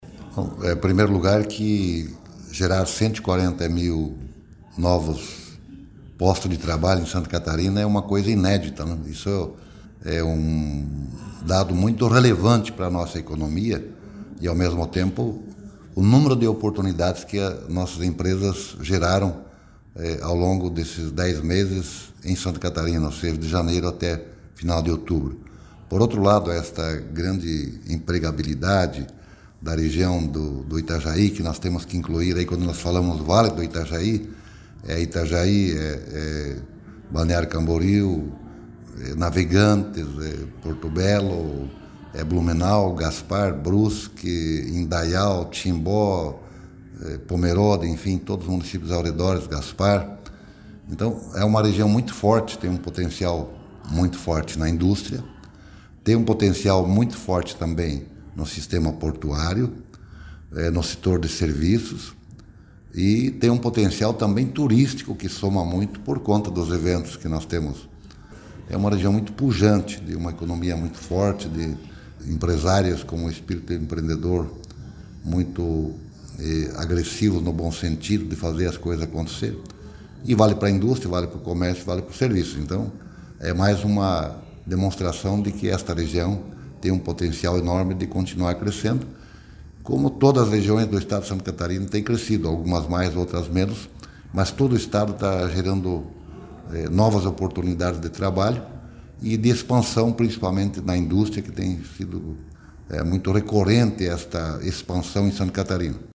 O secretário de Estado de Indústria, Comércio e Serviço, Silvio Dreveck, destaca os dados relevantes e a empregabilidade da região: